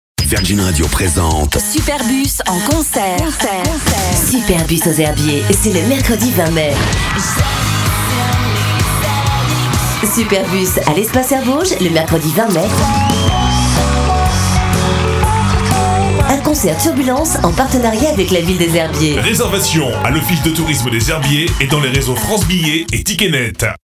Mairies, Communautés de Communes ou Union des Commerçants, nous avons réalisé pour eux les spots publicitaires qu'ils souhaitaient !